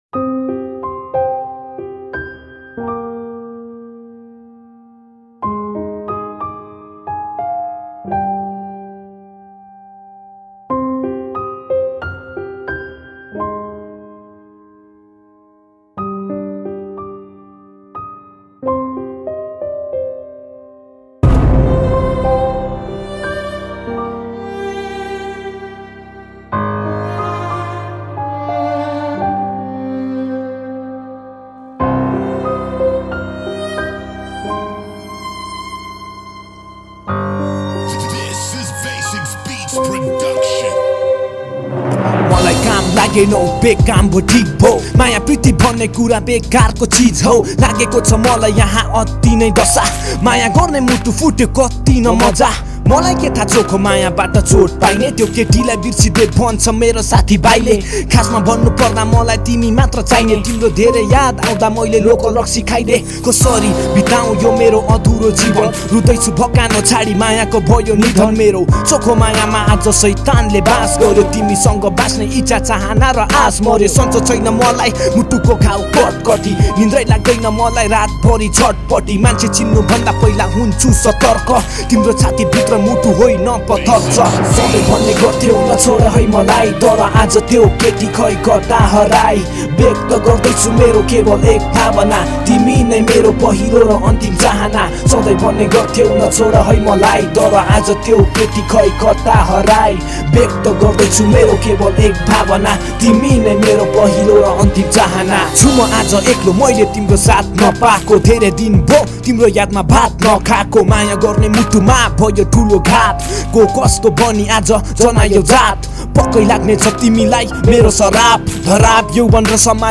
# Nepali Rap Mp3 Songs Download